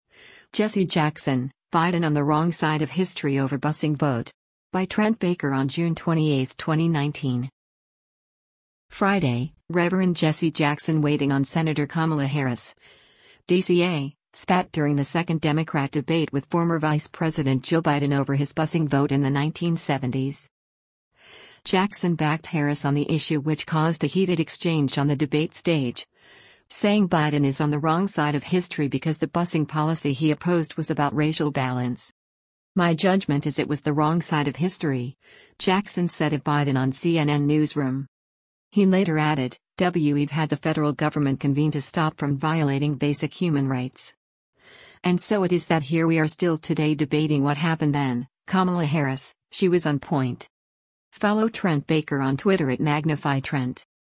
“My judgment is it was the wrong side of history,” Jackson said of Biden on “CNN Newsroom.”